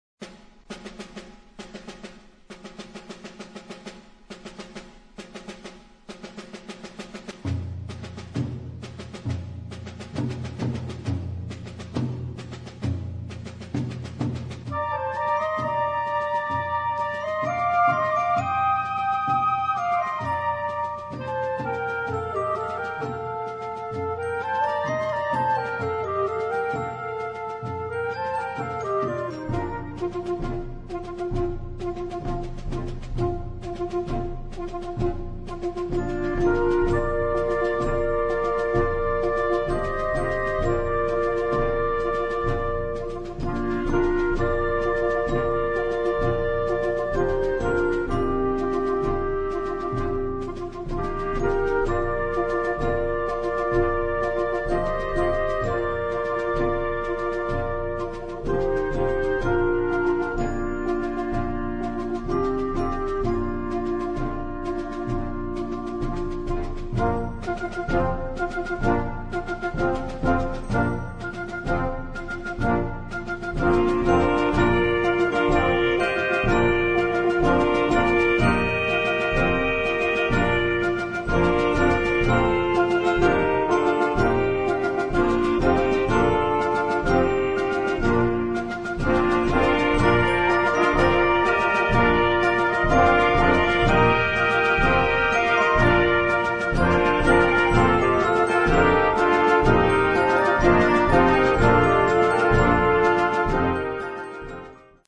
in the style of a bolero
Partitions pour orchestre d'harmonie des jeunes.